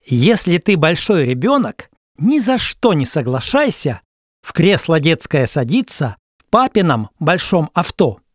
диктор )) нтк и dexp из днс за 2999 рублей